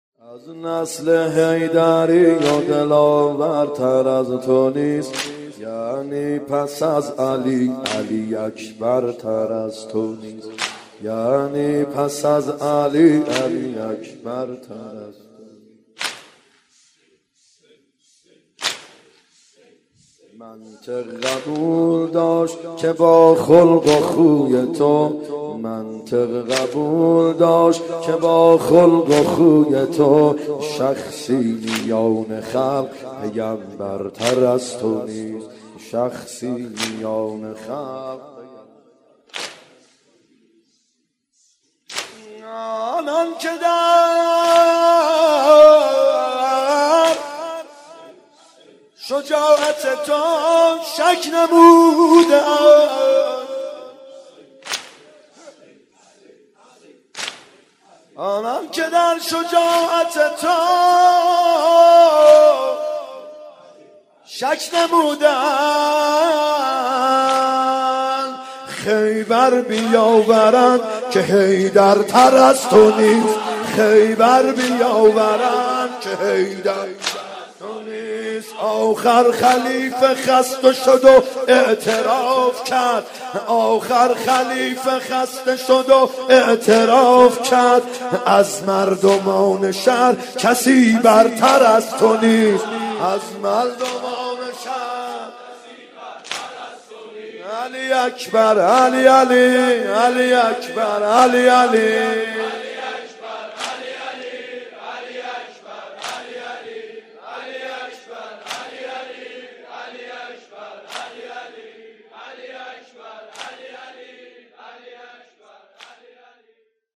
محرم 93( هیأت یامهدی عج)